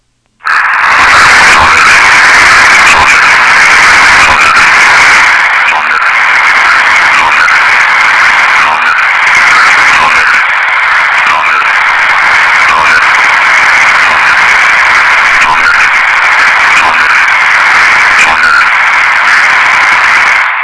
7156 кГц - неизвестная передача
Начало » Записи » Радиоcигналы на опознание и анализ